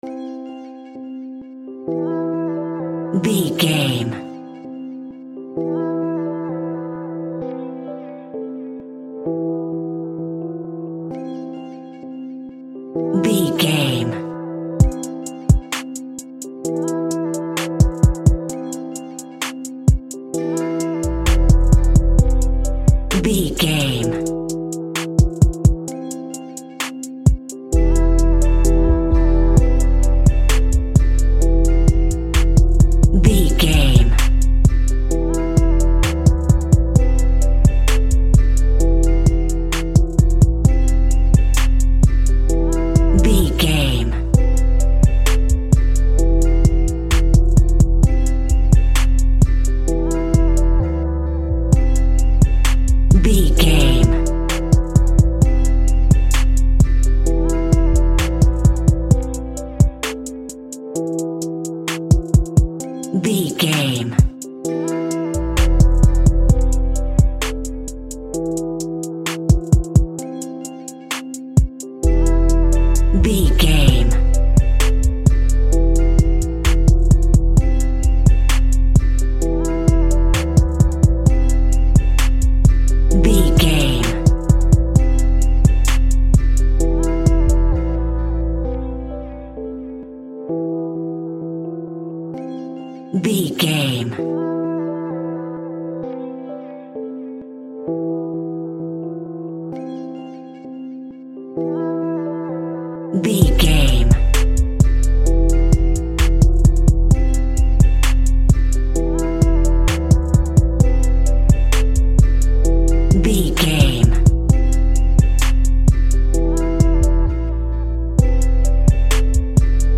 Ionian/Major
drums
smooth
calm
mellow
urban